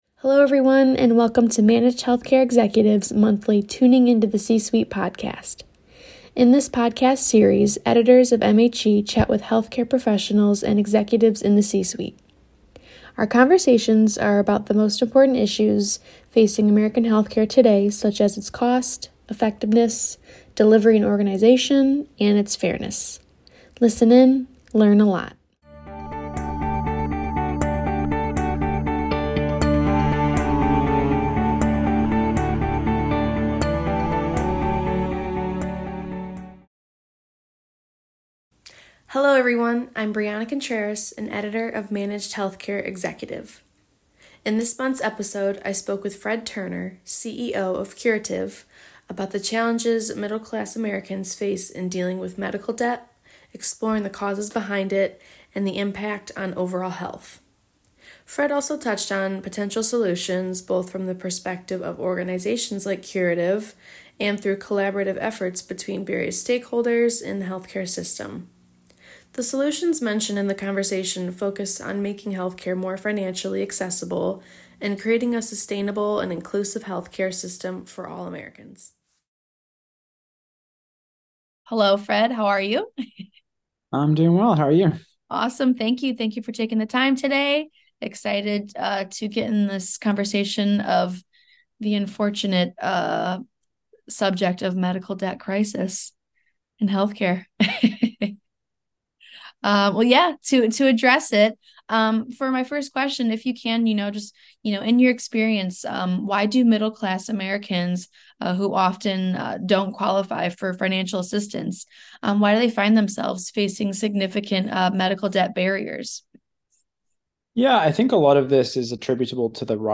The solutions mentioned in the conversation focus on making healthcare more financially accessible and creating a sustainable and inclusive healthcare system for all Americans.